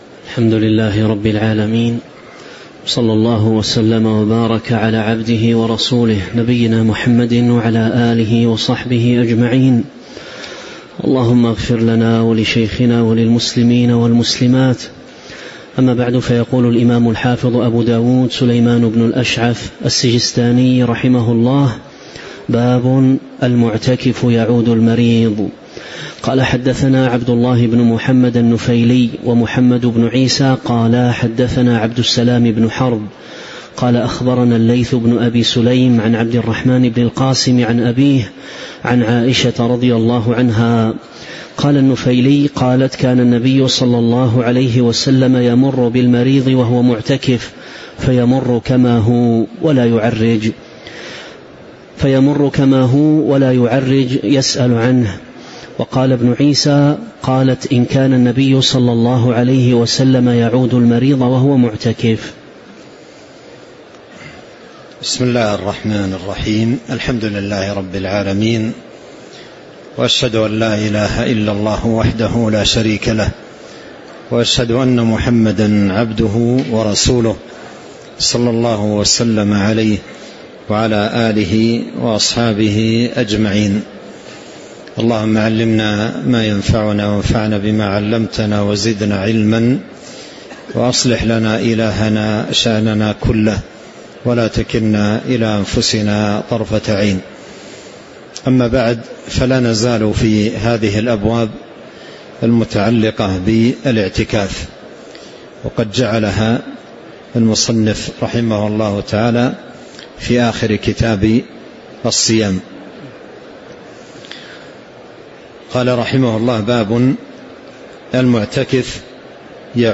تاريخ النشر ٢٤ رمضان ١٤٤٦ هـ المكان: المسجد النبوي الشيخ: فضيلة الشيخ عبد الرزاق بن عبد المحسن البدر فضيلة الشيخ عبد الرزاق بن عبد المحسن البدر قوله: باب المعتكف يعود المريض (022)